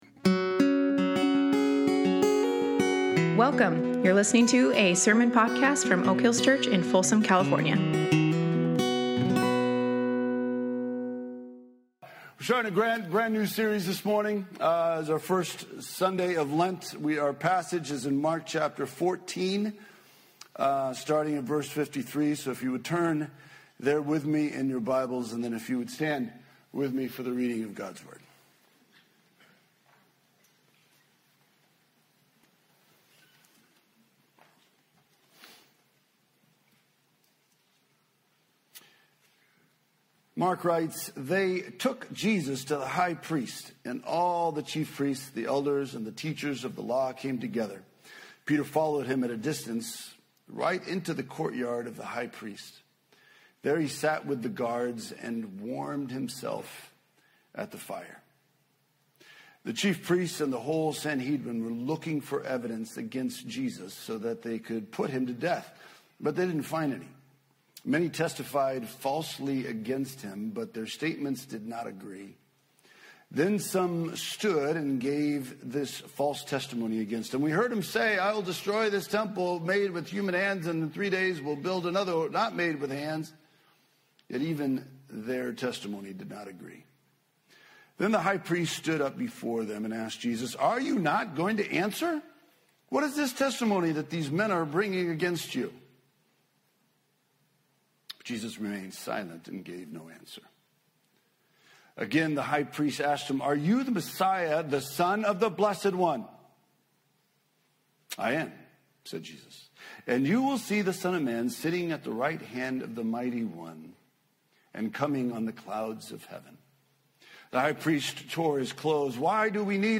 Mark 14:53-65 Service Type: Sunday Morning Our King Jesus is the almighty ruler of the universe.